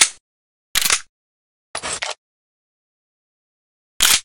quadroReload.ogg